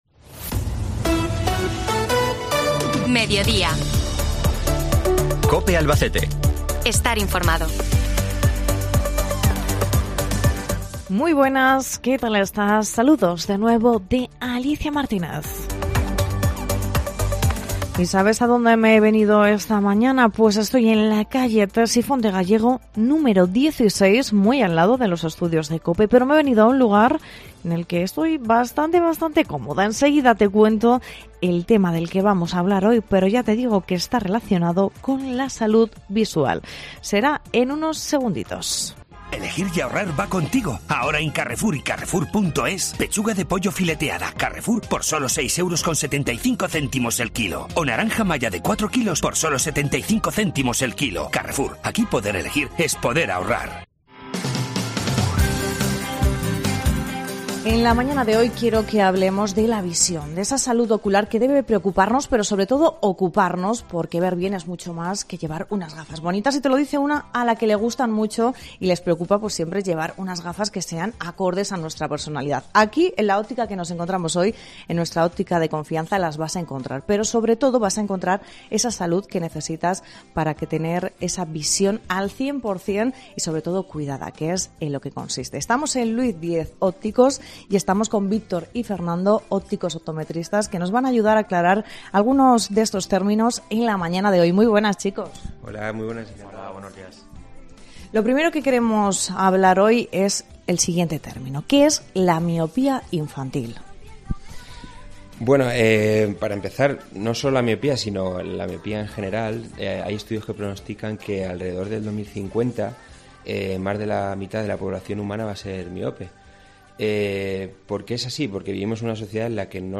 Entrevista
Cope Albacete realiza hoy su magazine de Mediodía Cope desde